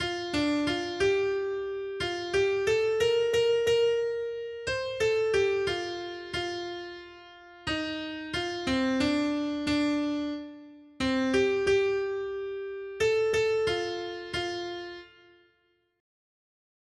Noty Štítky, zpěvníky ol65.pdf responsoriální žalm Žaltář (Olejník) 65 Iz 12, 2-6 Skrýt akordy R: Budete vážit vodu s radostí z pramenů spásy. 1.